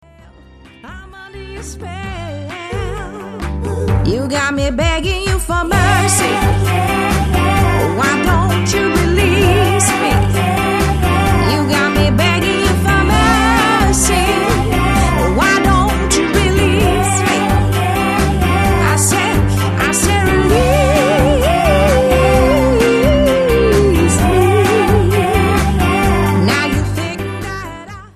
Duo  - Trio - 4p Band - 5p Band
Duo + Backing Sounds